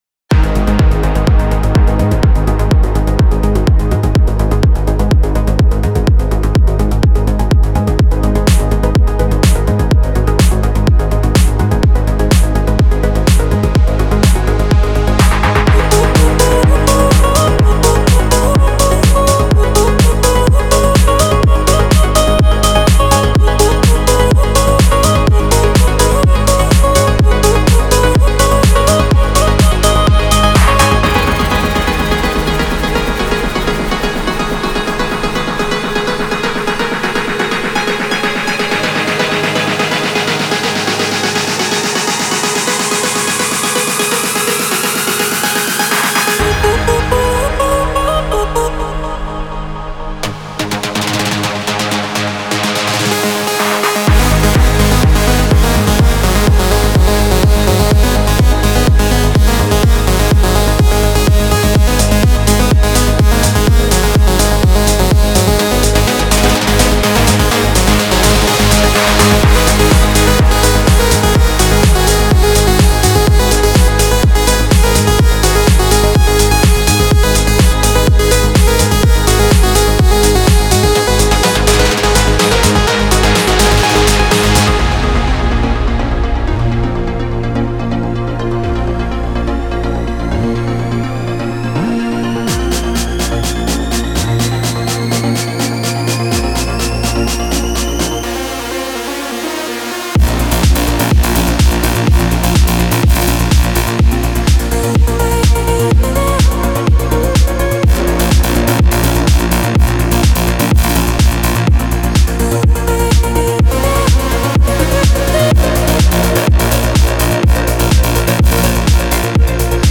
Type: Serum Midi Samples
Melodic Techno Techno